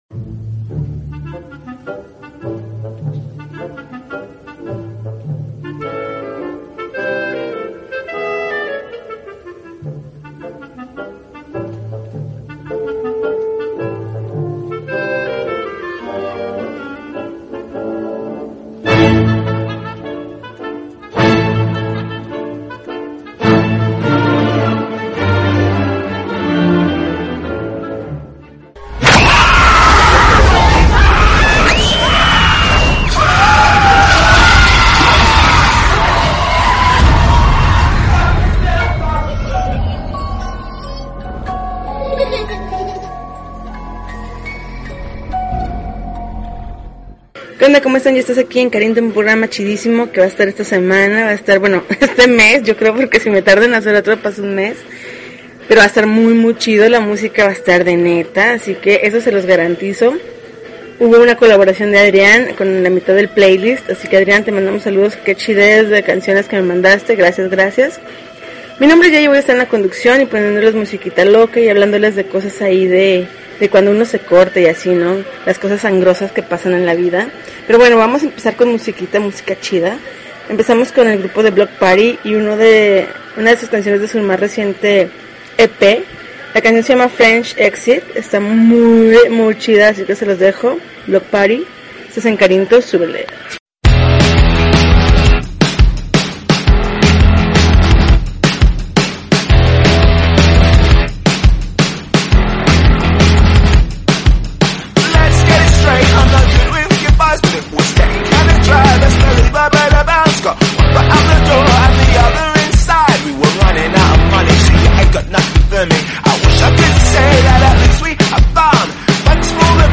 October 14, 2013Podcast, Punk Rock Alternativo